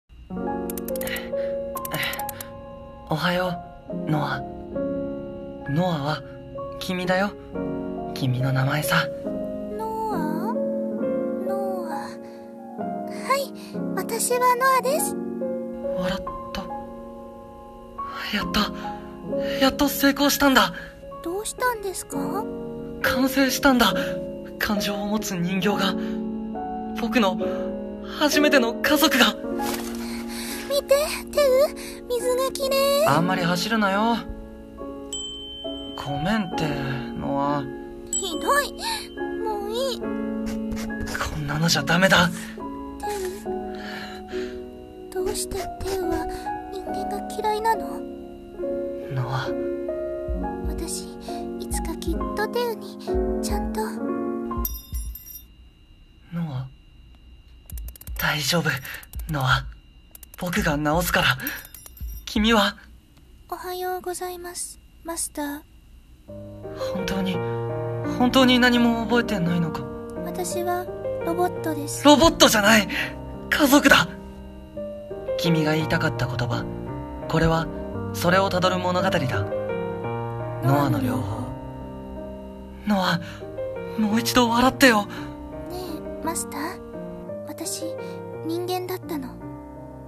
【映画予告風声劇】ノアの療法